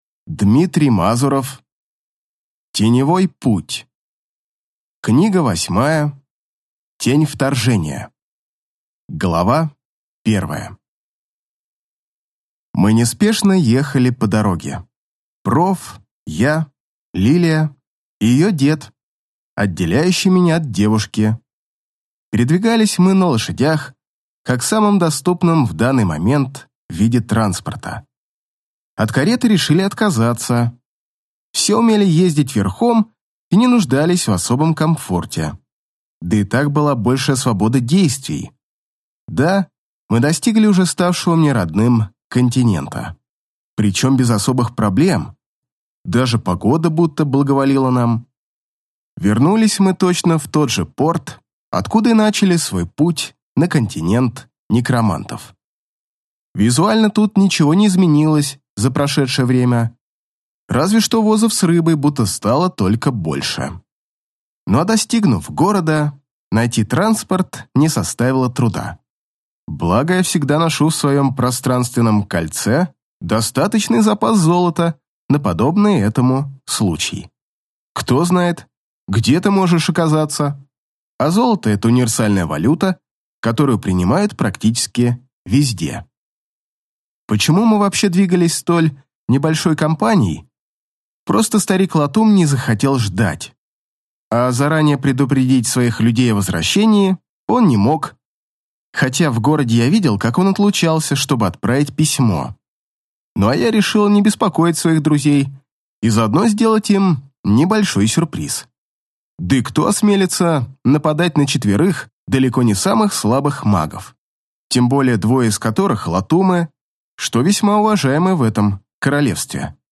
Аудиокнига Тень вторжения | Библиотека аудиокниг
Прослушать и бесплатно скачать фрагмент аудиокниги